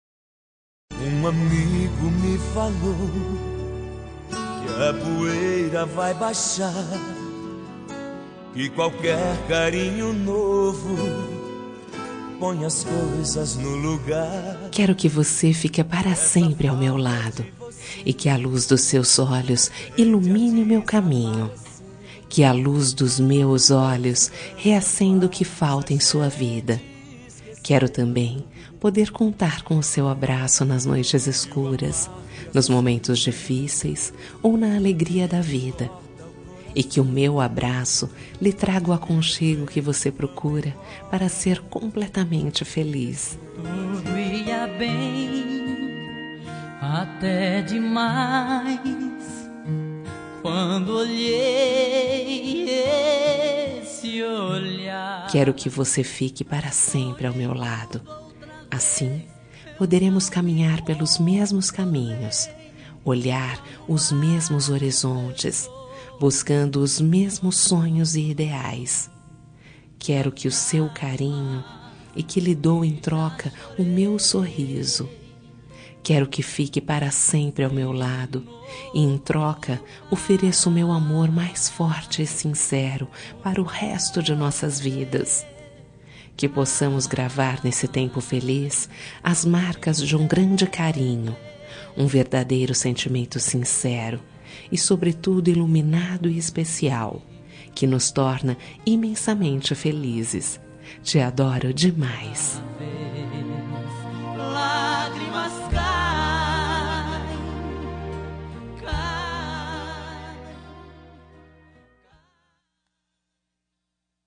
Toque para Não Terminar – Voz Feminina – Cód: 476 – Fique ao meu Lado
476-fique-ao-meu-lado-fem.m4a